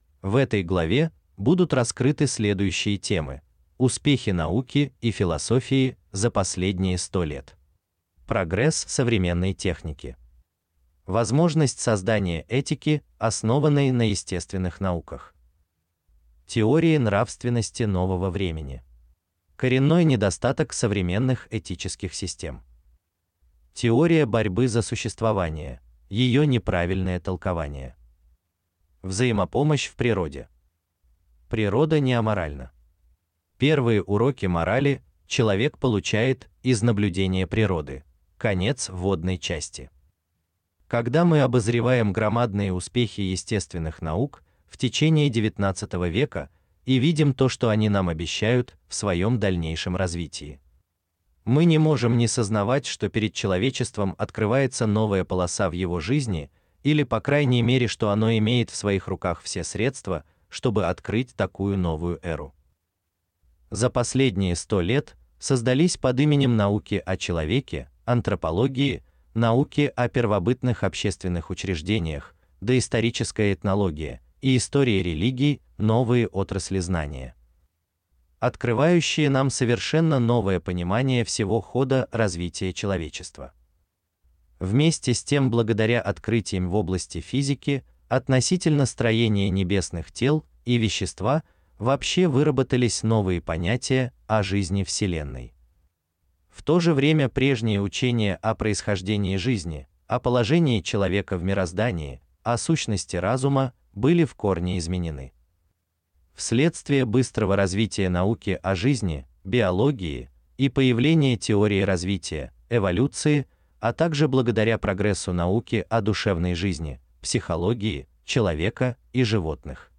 Аудиокнига Анархия и нравственность | Библиотека аудиокниг